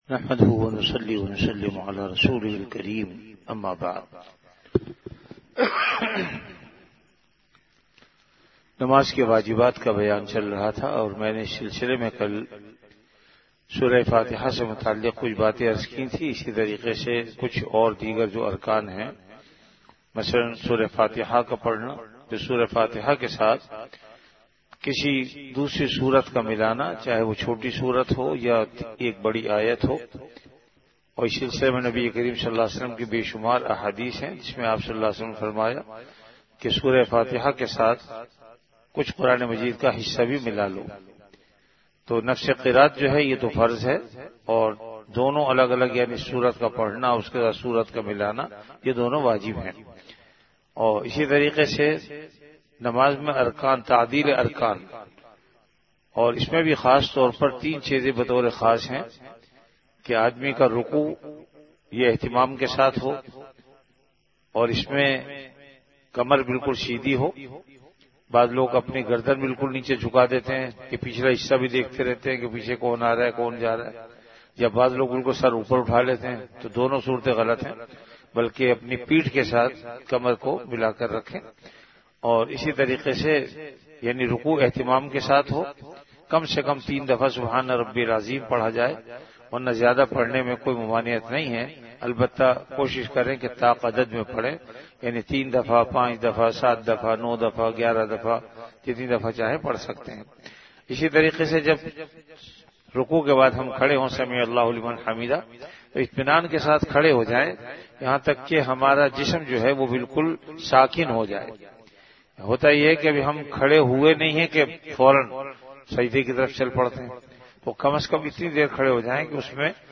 Ramadan - Dars-e-Hadees · Jamia Masjid Bait-ul-Mukkaram, Karachi